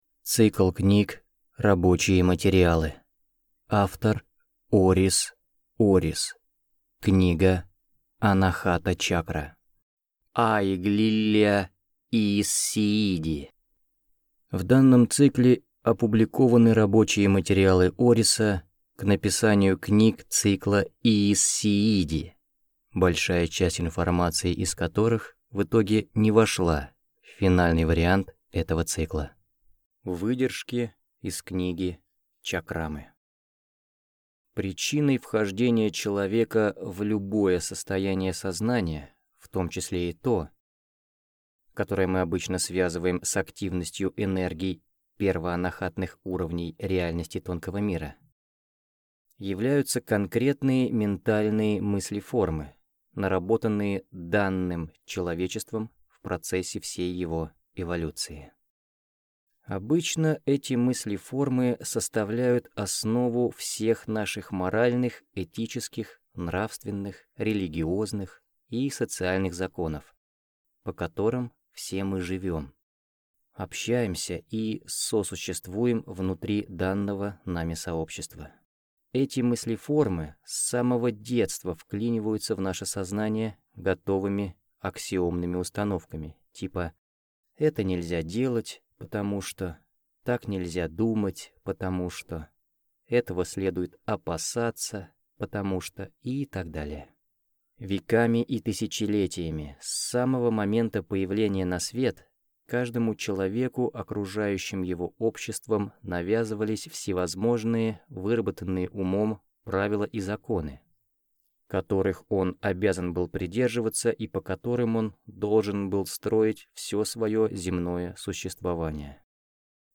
Аудиокнига Анахата чакра | Библиотека аудиокниг